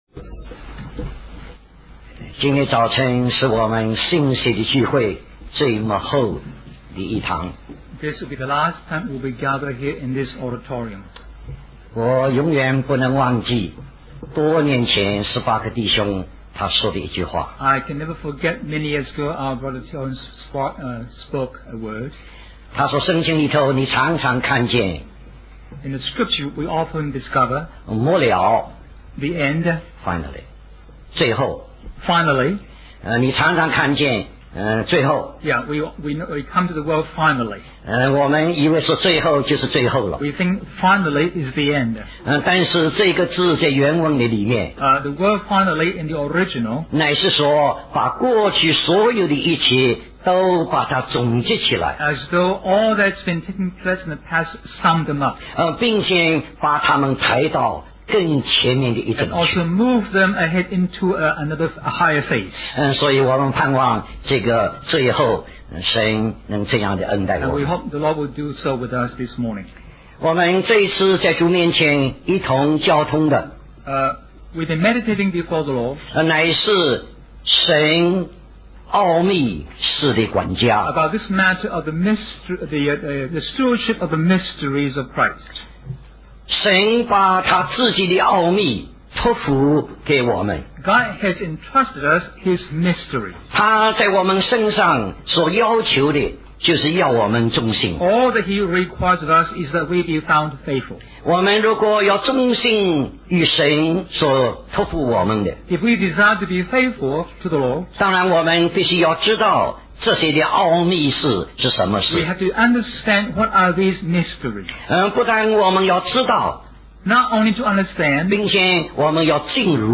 Special Conference For Service, Singapore